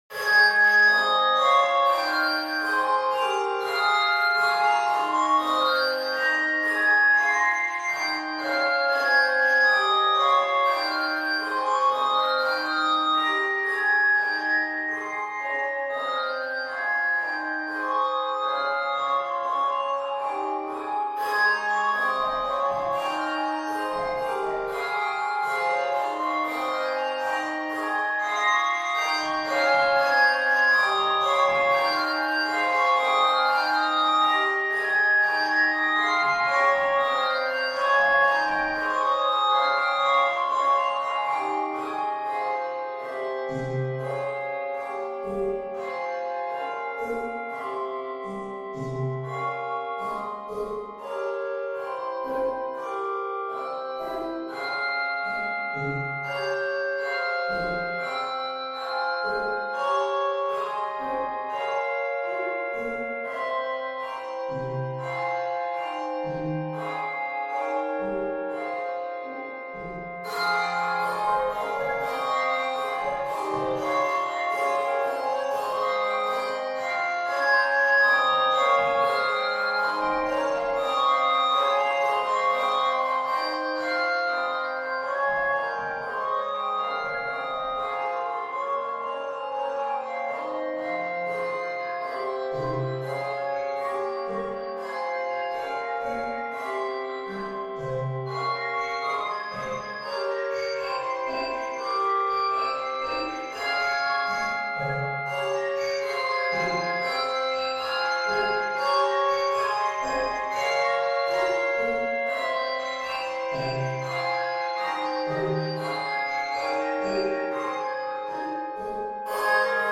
Key of Ab Major.